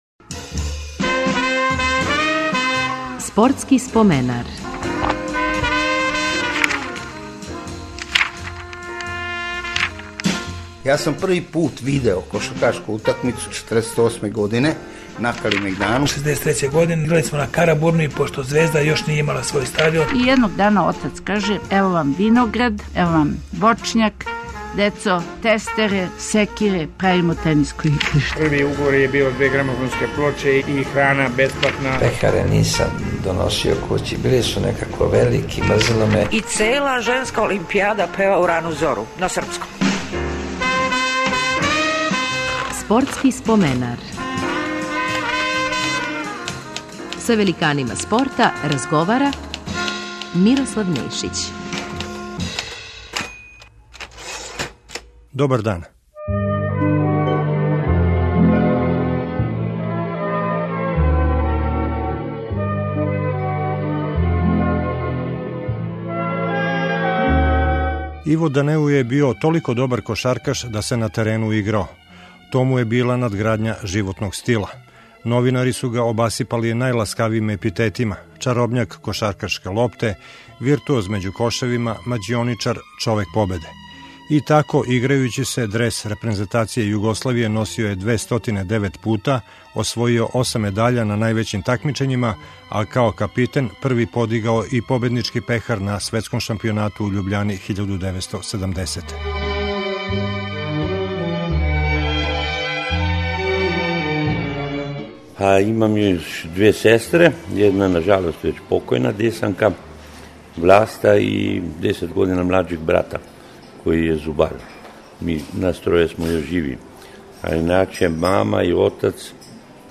Гост емисије биће један од најбољих југословенских кошаркаша свих времена Иво Данеу.